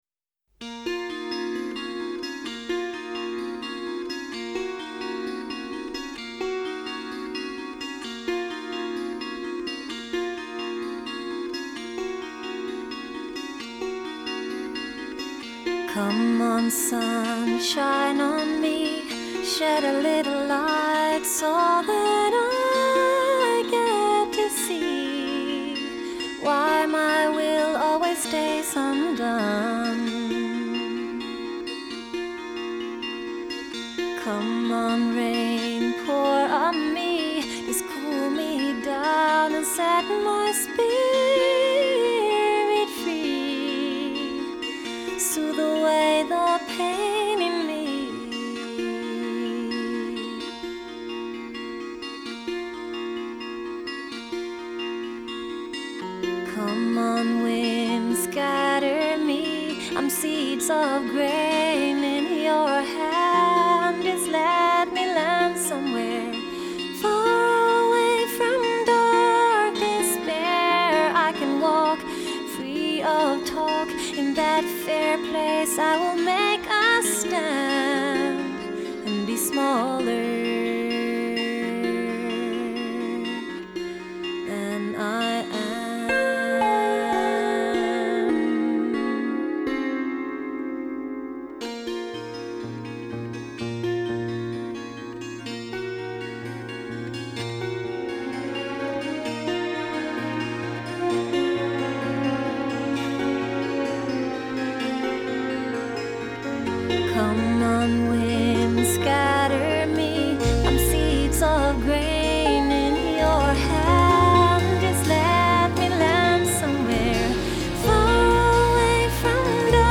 Genre : Vocal Jazz